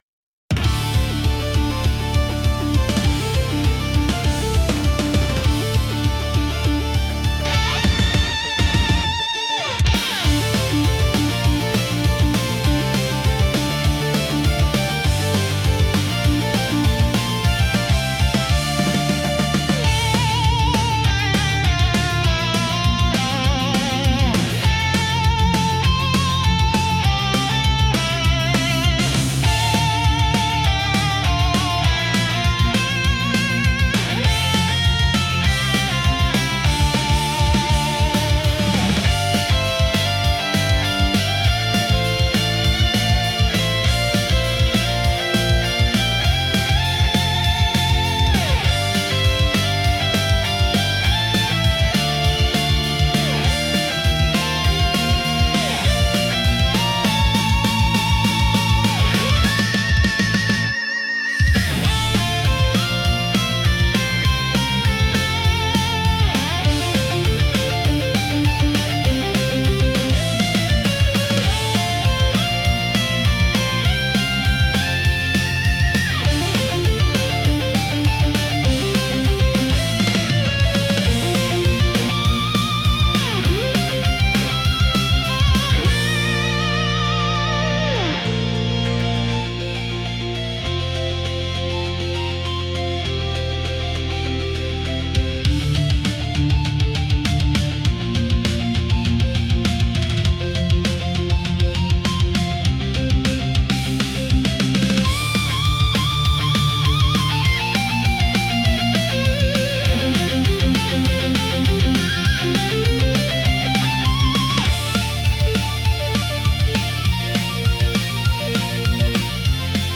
Genre: Epic Mood: Anime Editor's Choice